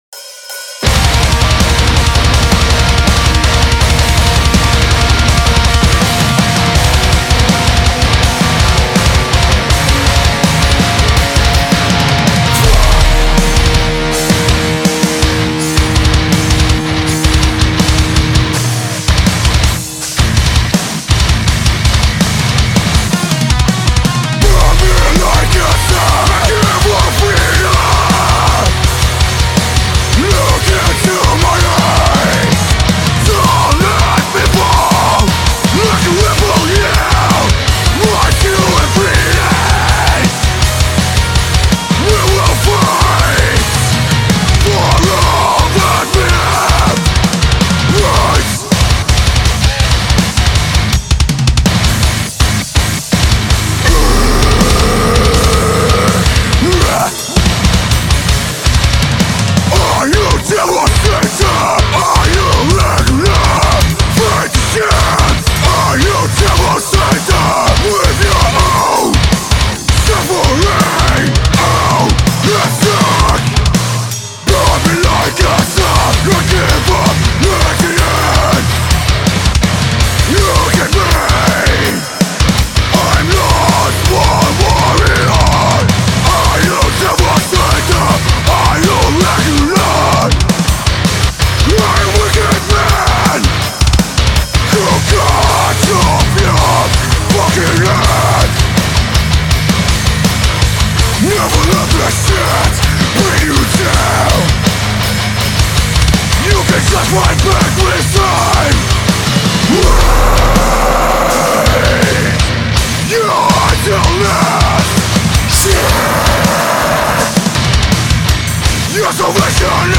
вокал
гитара
бас
ударные